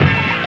23 HIT 1.wav